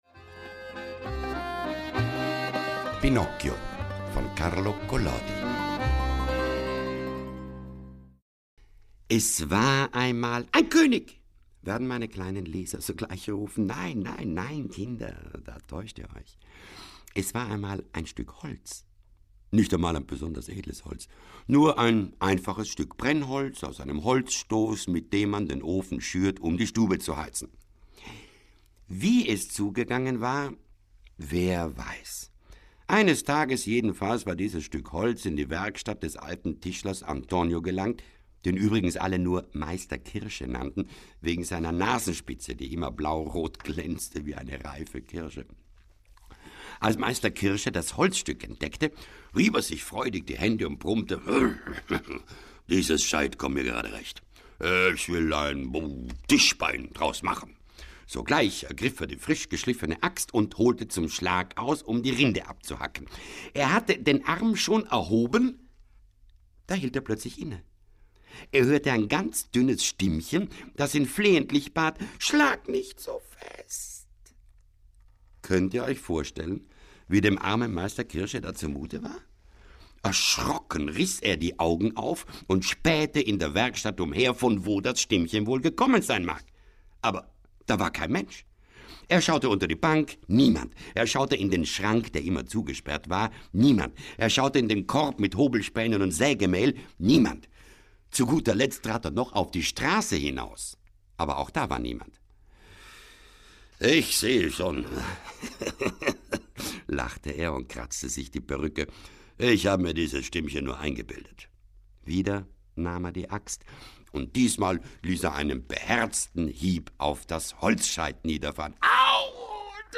Konrad Beikircher (Sprecher)
Mit großer Freude und Sorgfalt produzierte er mit seinen Musikerfreunden spezielle Instrumentalstücke, die die Lesung untermalen und begleiten.